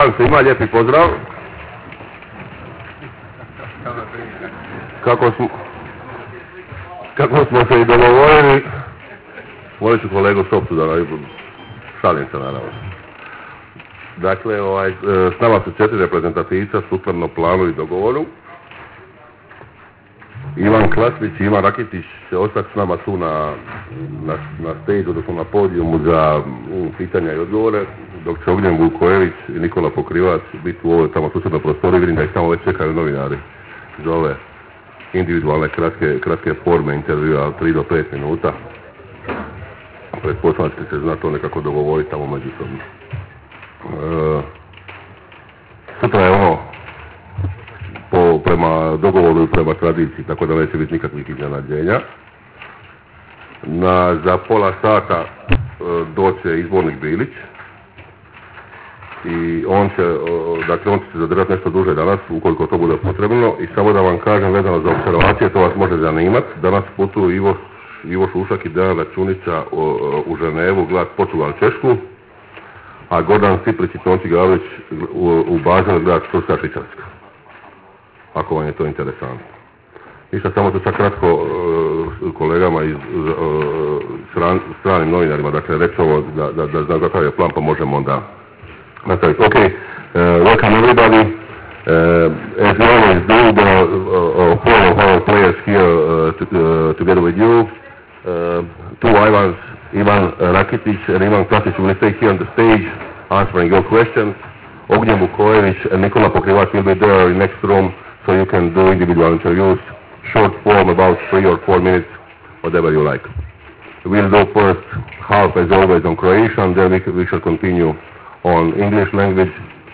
Dva dana nakon Beča, a prije Klagenfurta, Ivan Klasnić i Ivan Rakitić družili su se s novinarima u Bad Tatzmannsdorfu.
Audio konferencija
Izjave za medije Ivana Klasnića i Ivana Rakitića